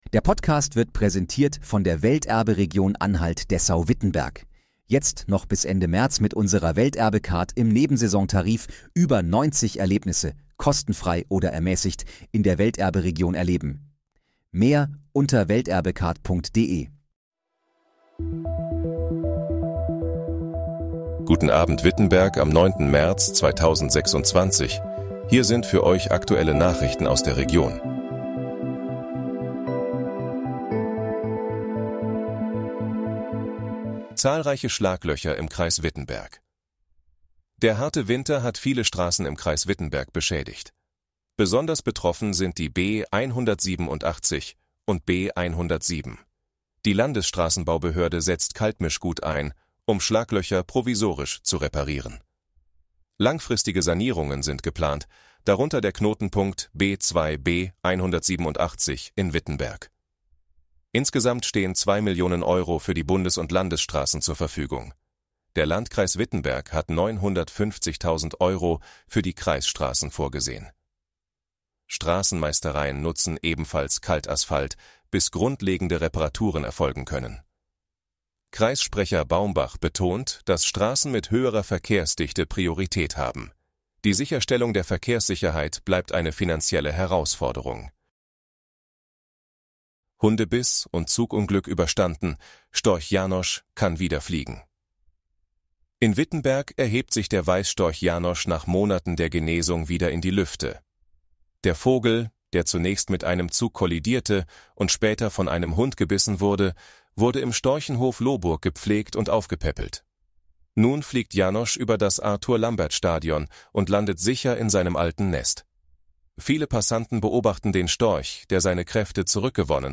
Guten Abend, Wittenberg: Aktuelle Nachrichten vom 09.03.2026, erstellt mit KI-Unterstützung
Hier sind die aktuellen Nachrichten für Wittenberg und die Region